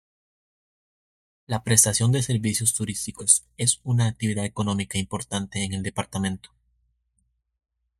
Pronounced as (IPA) /aɡtibiˈdad/